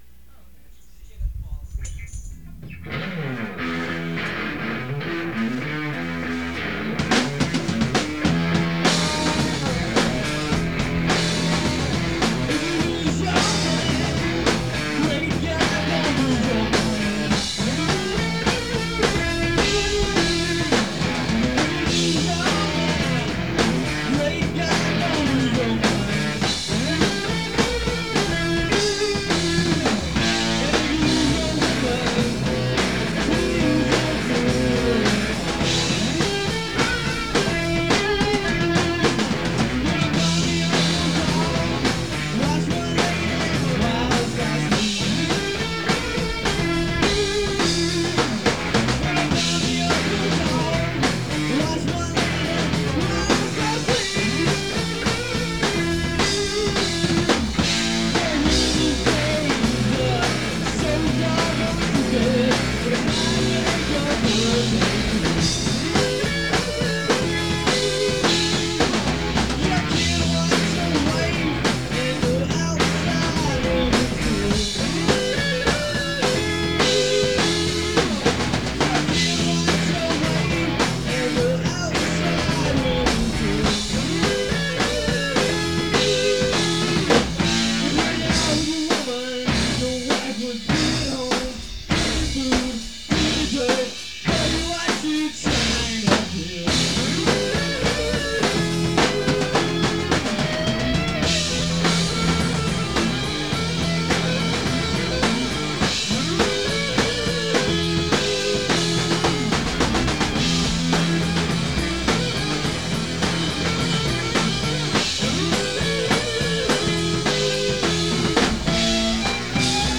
the old blues classic